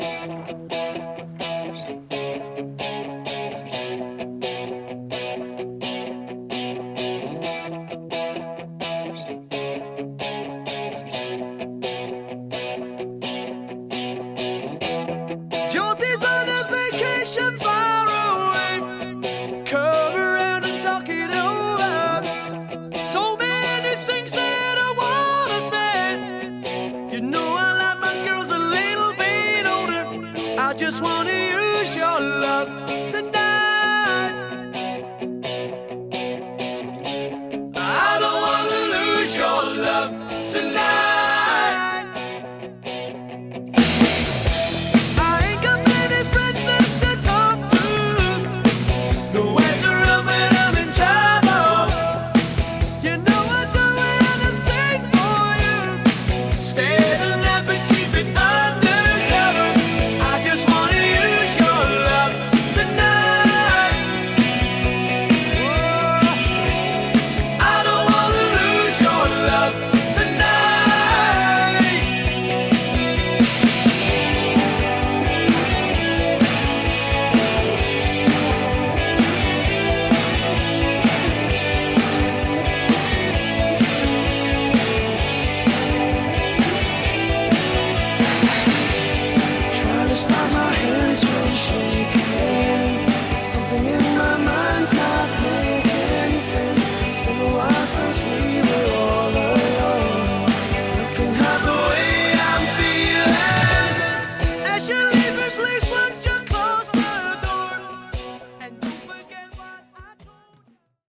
BPM129-130
Audio QualityMusic Cut